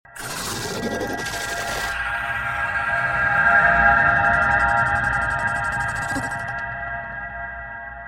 Horror Generative AI & Sound Sound Effects Free Download